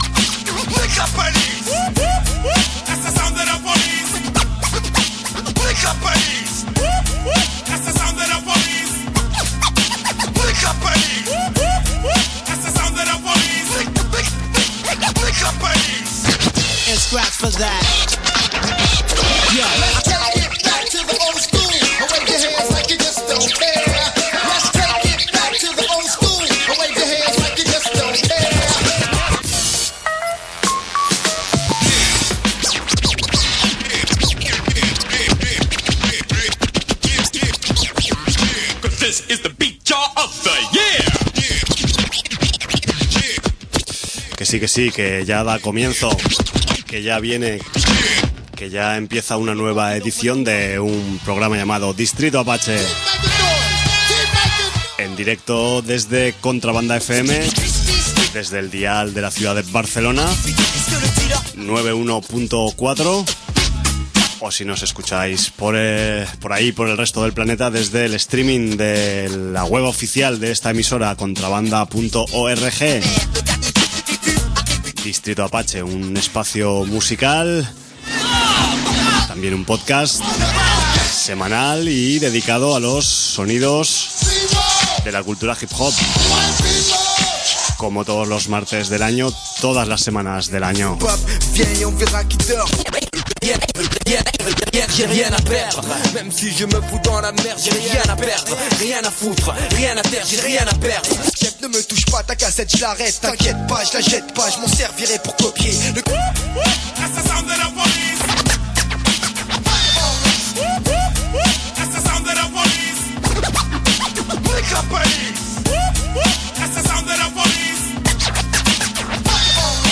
Todo un conglomerado de rimas y producciones